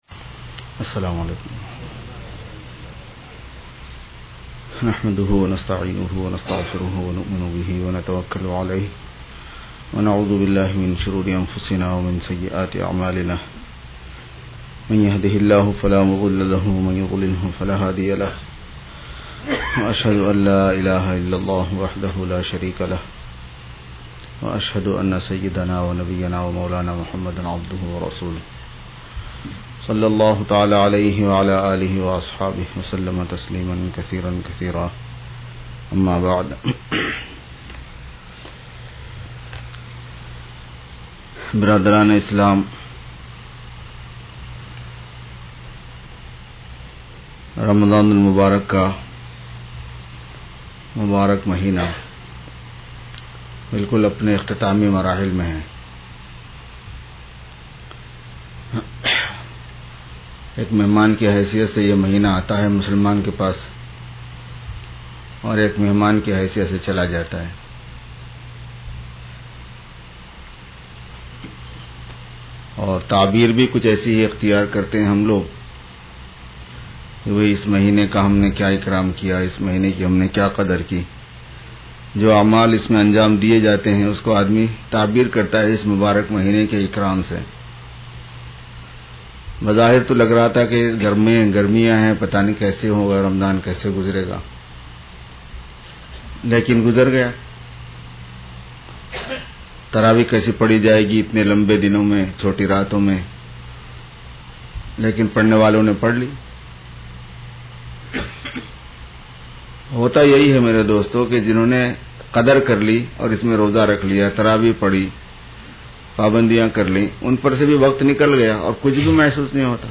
Khutbat e Juma 02-Sep-2011